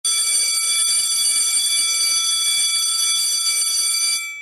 Catégorie Bruitages